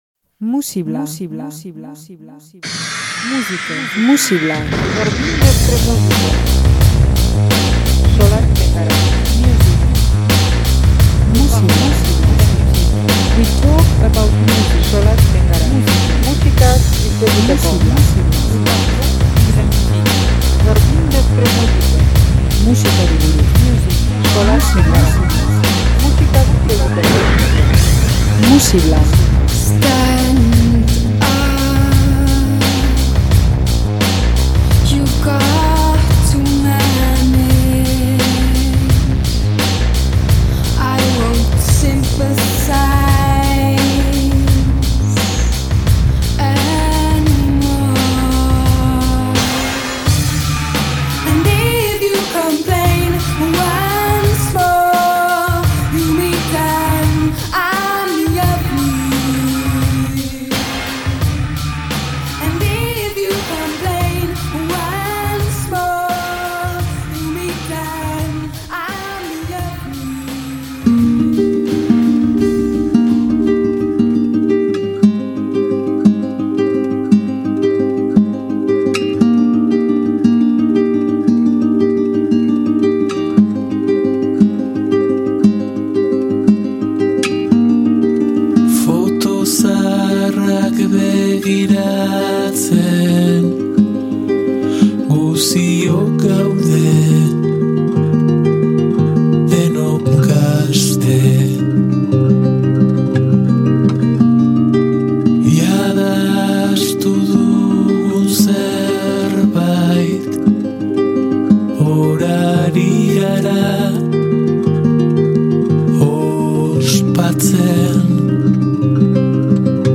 amerikana eta poparen artean grabitatzen duen lana da.